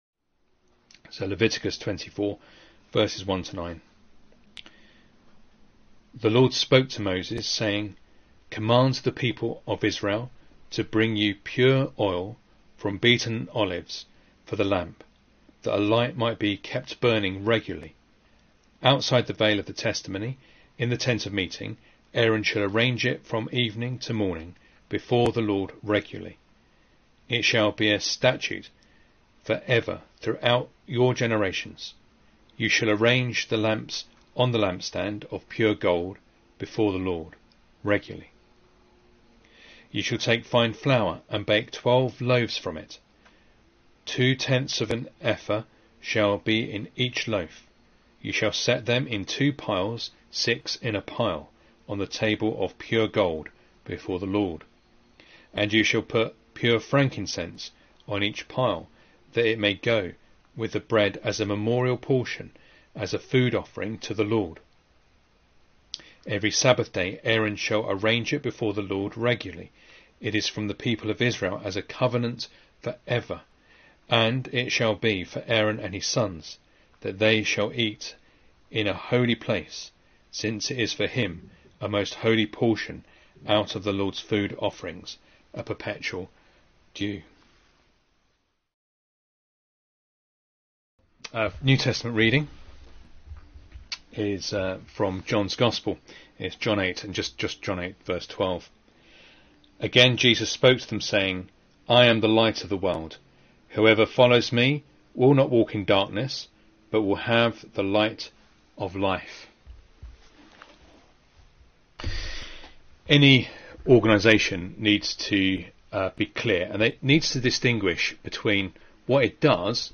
Leviticus Passage: Leviticus 24:1-9, John 8:12 Service Type: Sunday Morning Reading and Sermon Audio